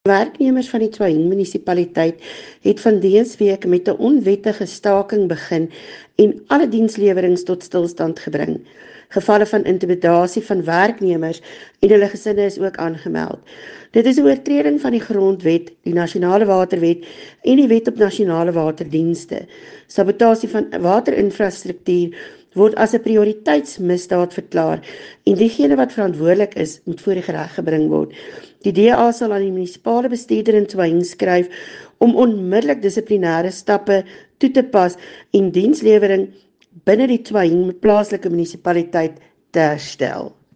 Issued by Soret Viljoen – DA Councillor - Tswaing Local Municipality
Note to Broadcasters: Please find attached soundbite in